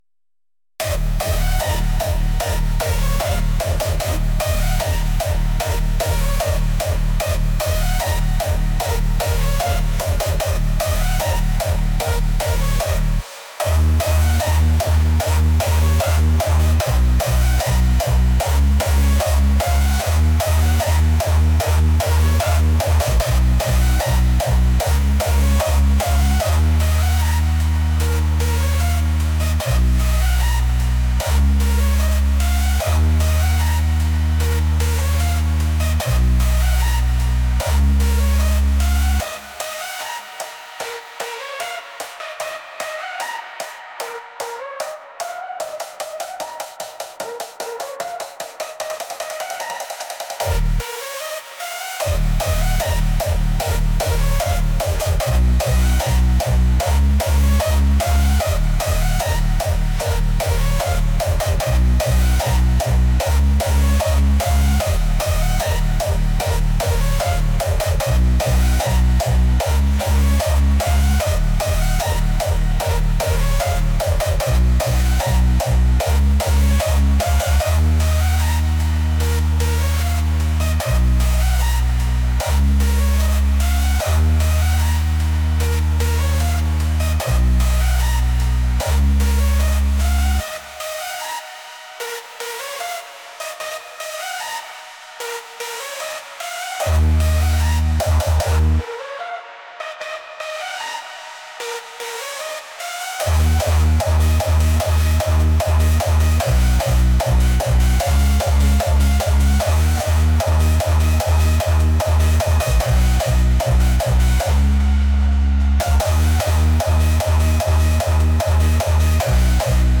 energetic | intense